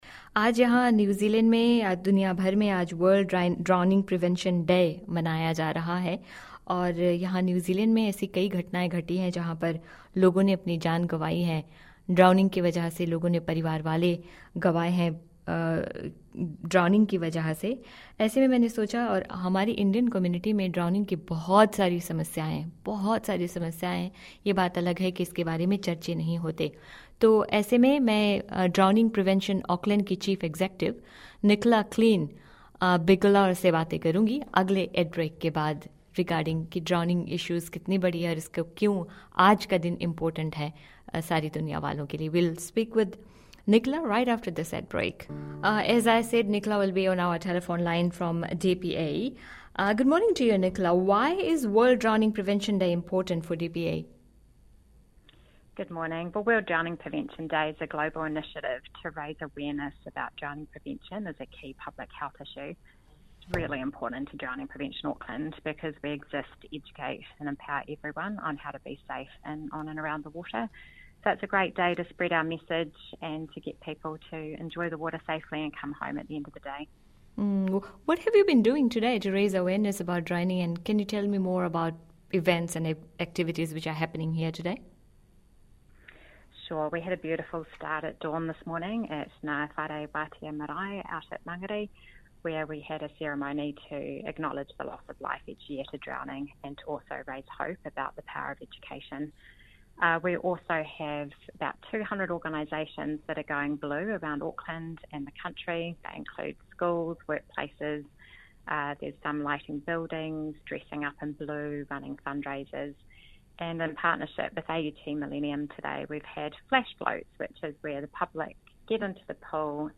They discuss the reasons behind why people who are new to Aotearoa New Zealand are more at risk around our waterways and about Float first – the simple skill everyone can learn to increase their chances of survival if they do end up in trouble in the water. Listen to the full interview below.